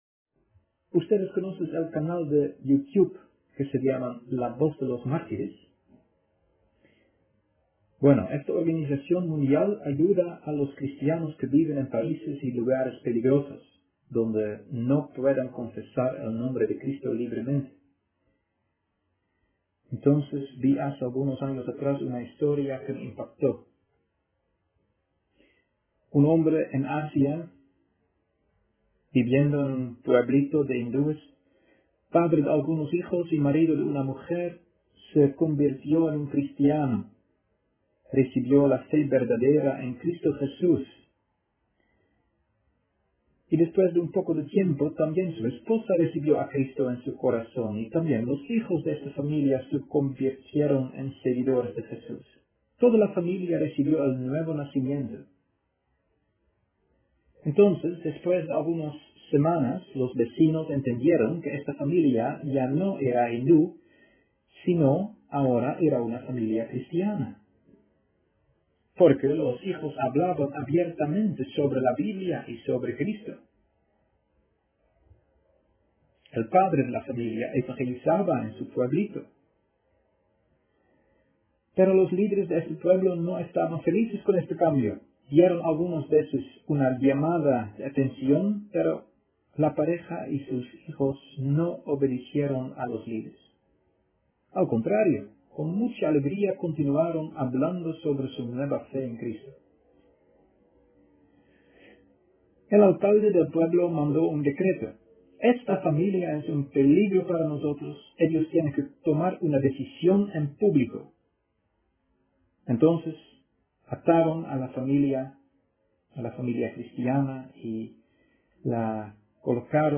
Tipo: Sermón